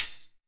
PTAMBOURIN18.wav